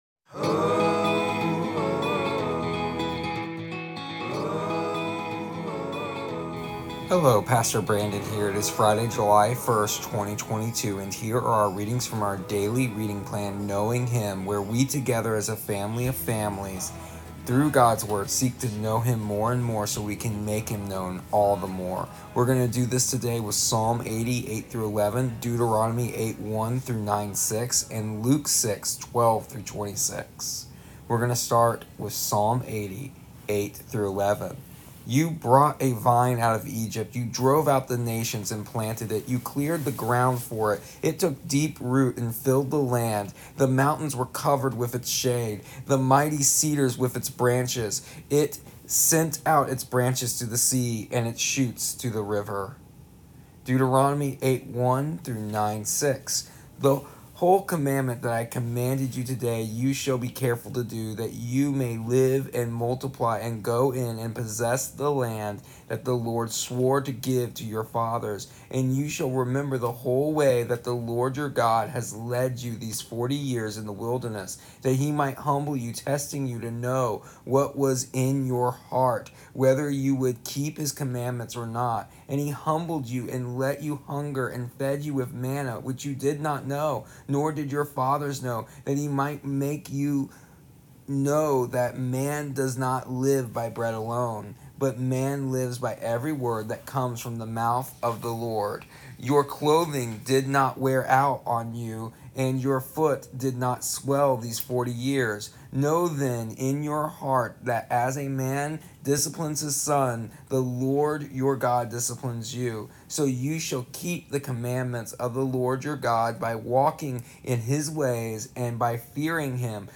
Here is the audio version of our daily readings from our daily reading plan Knowing Him for July 1st, 2022.